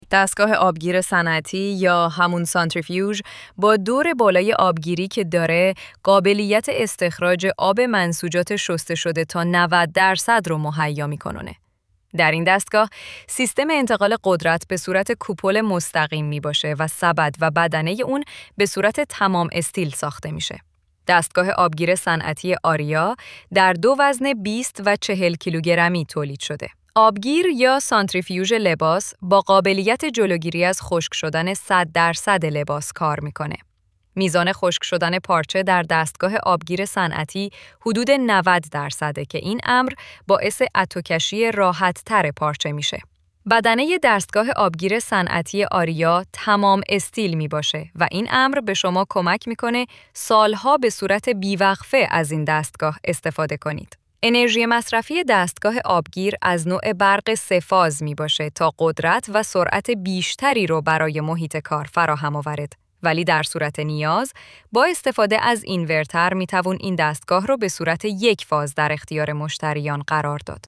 ElevenLabs_Text_to_Speech_audio-3.mp3